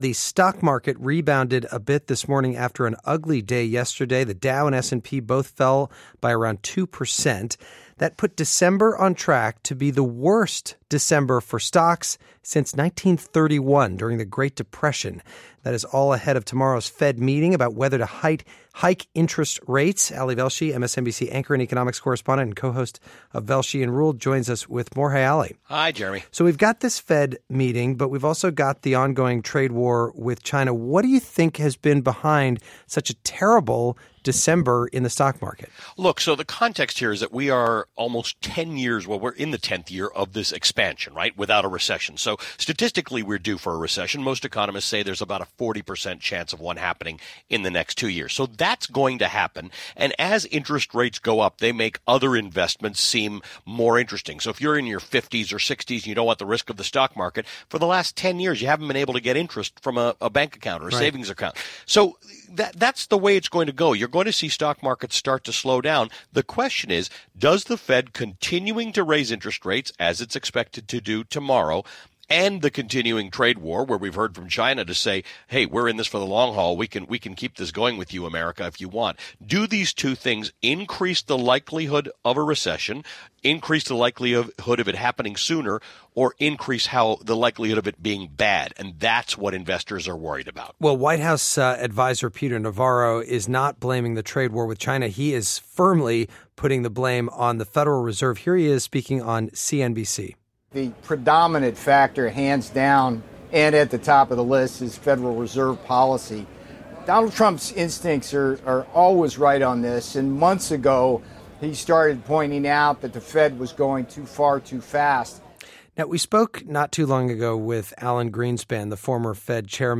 The Dow, S&P and Nasdaq all fell by around 2 percent Monday. Here & Now's Jeremy Hobson discusses with MSNBC's Ali Velshi, co-host of "Velshi & Ruhle."